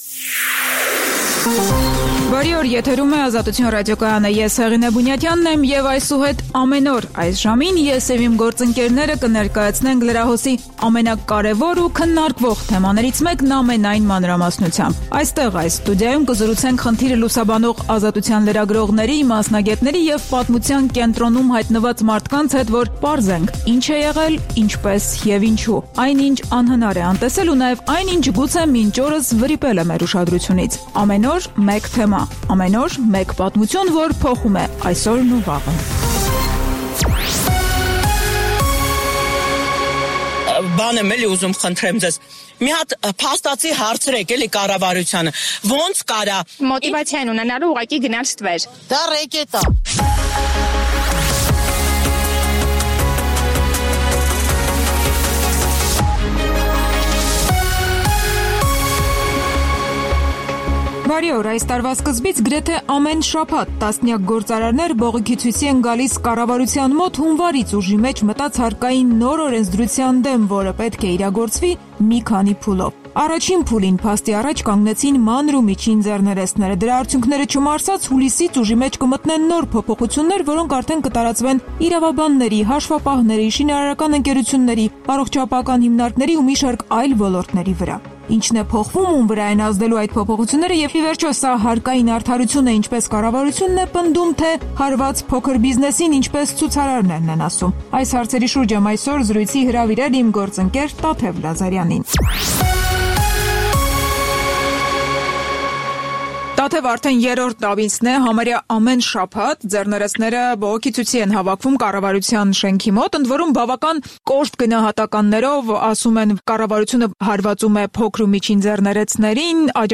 Տեղական եւ միջազգային լուրեր, ռեպորտաժներ զարգացող իրադարձությունների մասին, այդ թվում՝ ուղիղ եթերում, հարցազրույցներ, տեղական եւ միջազգային մամուլի տեսություն: